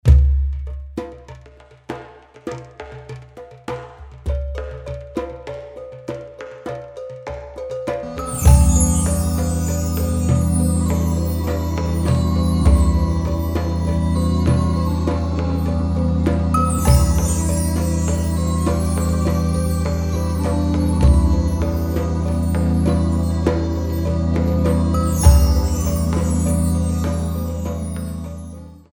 fourteen beats